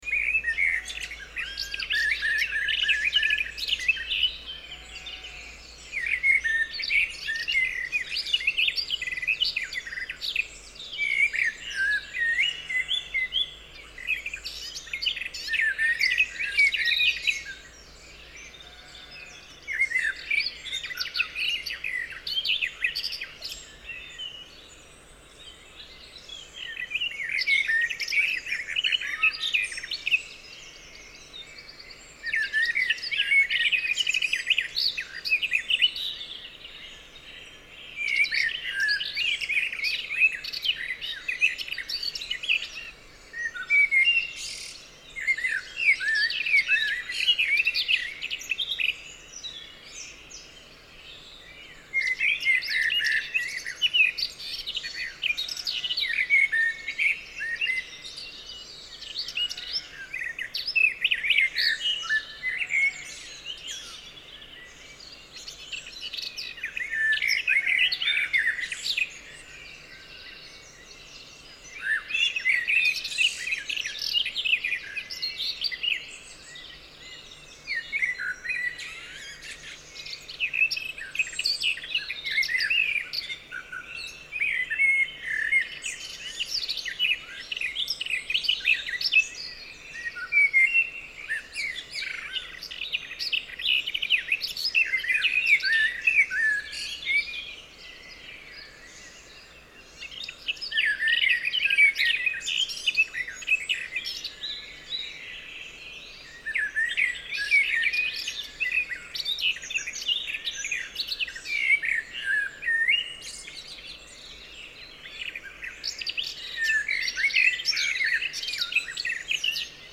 At 3.30 am it is already light in Scotland in the middle of June. I recorded this near a forest in Tayside, using those Shure WL 183 microphones, a FEL preamp into an iriver ihp-120.
Sounds >> Birds >> Morning Morning in the Countryside Birdsongs in Scotland You may download the mp3 audio file under cc 4.0 license here (27.47 MB).